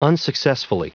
Prononciation du mot unsuccessfully en anglais (fichier audio)
Prononciation du mot : unsuccessfully